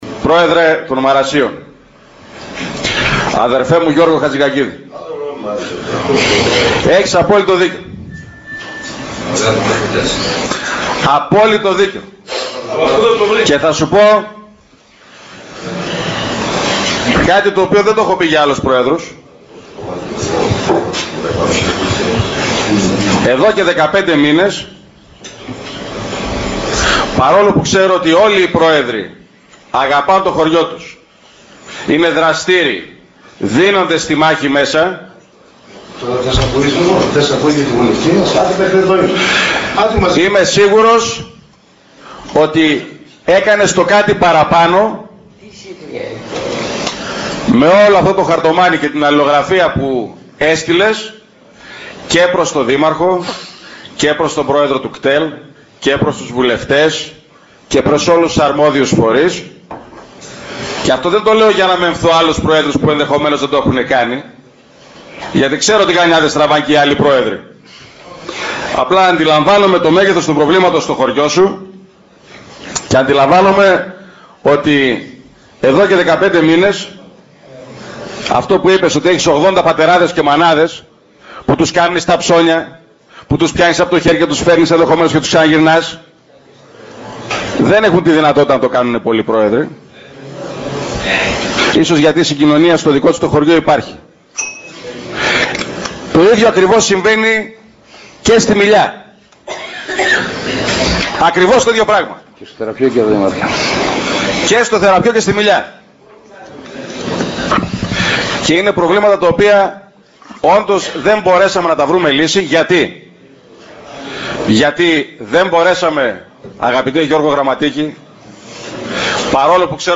Δήμαρχος Ορεστιάδας για απομόνωση χωριών